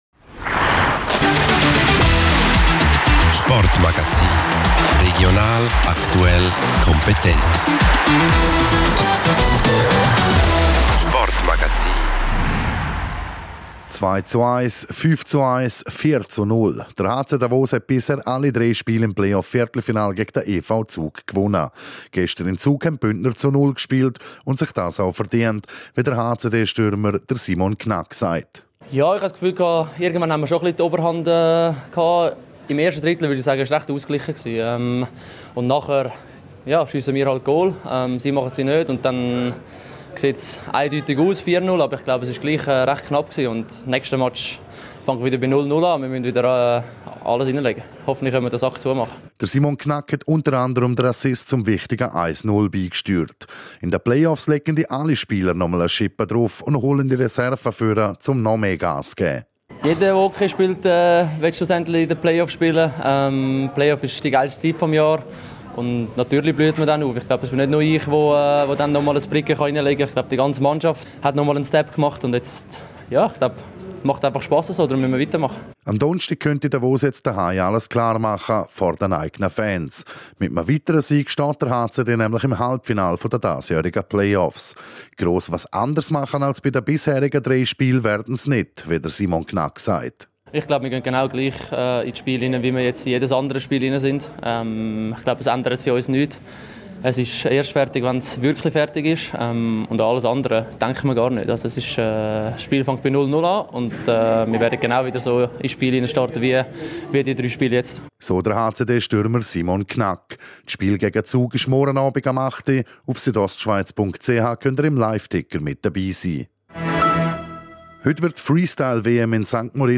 Stimmen der Athletinnen und Athleten aus dem Sendegebiet zum Start der Freestyle-WM.
18:00 Uhr Regionalsport Merken Like 1 Dislike 0 Teilen Facebook E-Mail WhatsApp Link